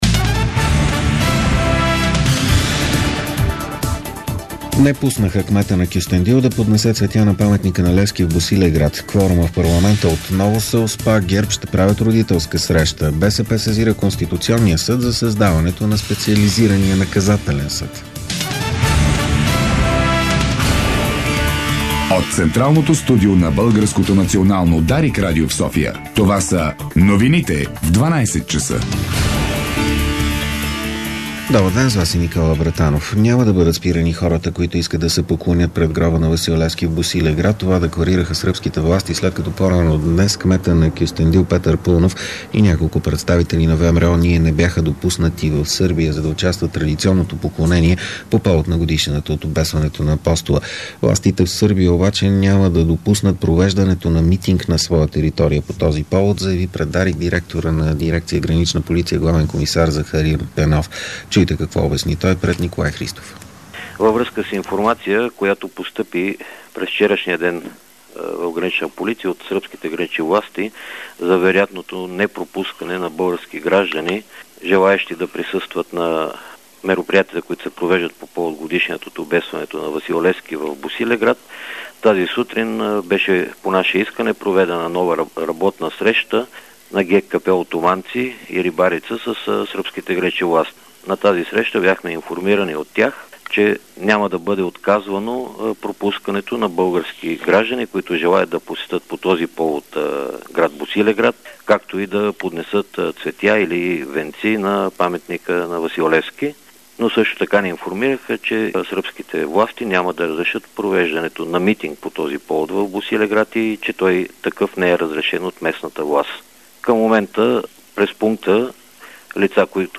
Обедна информaционна емисия - 18.02.2011